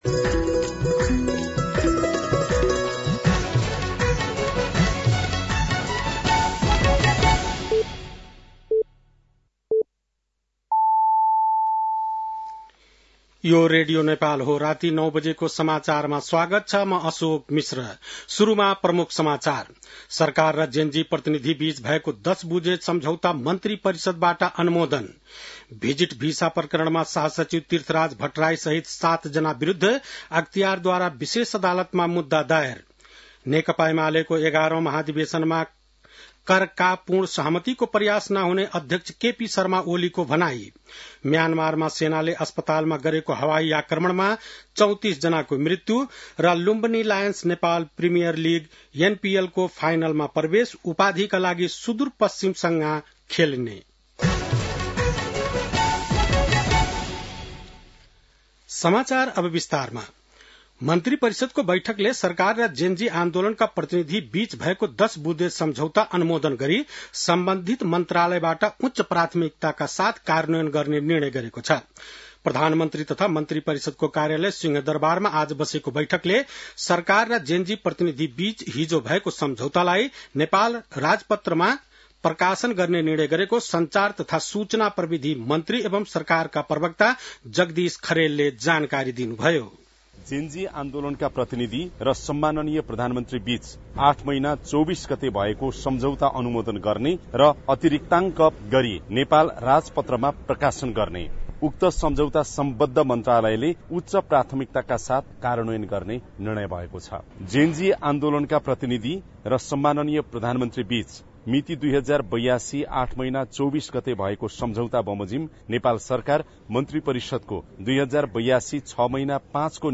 बेलुकी ९ बजेको नेपाली समाचार : २५ मंसिर , २०८२
9-PM-Nepali-NEWS-8-25.mp3